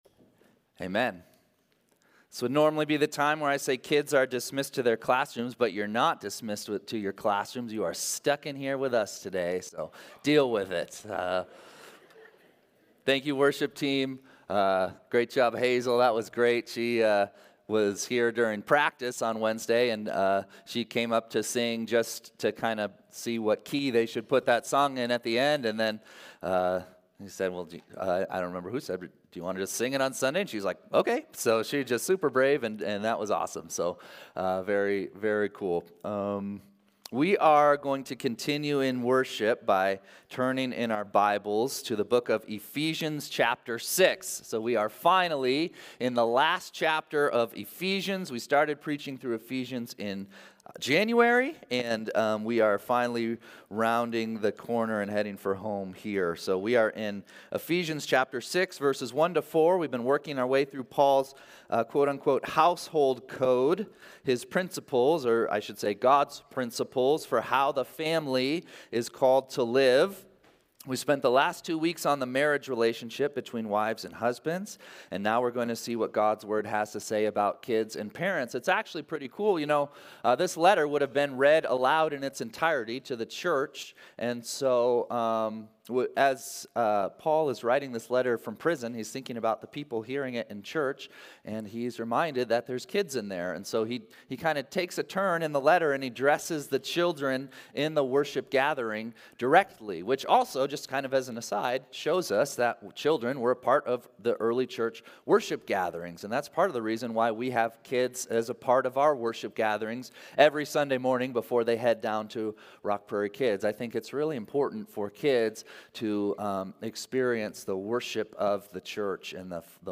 Sunday-Service-7-28-24.mp3